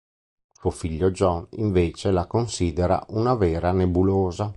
Read more a male given name and surname in English Frequency A2 Pronounced as (IPA) /ˈd͡ʒɔn/ Etymology Borrowed from English John.